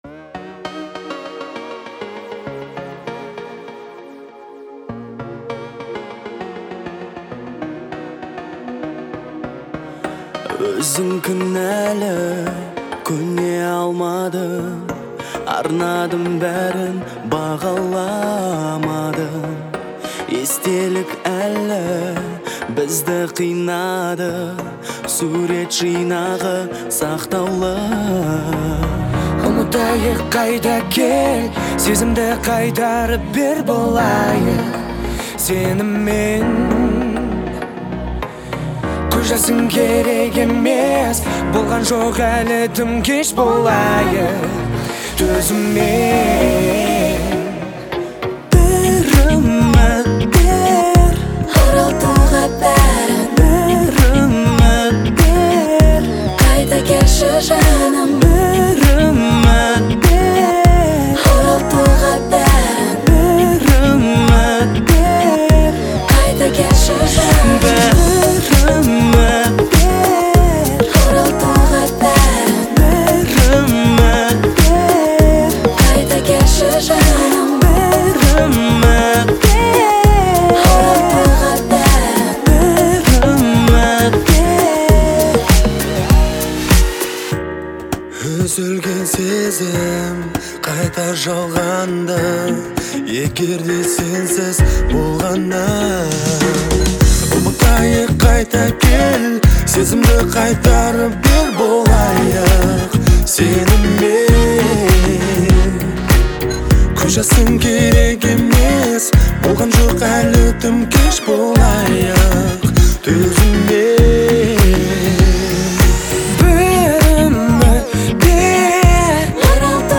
вдохновляющая а капелла композиция
исполняющаяся в жанре поп и народной музыки.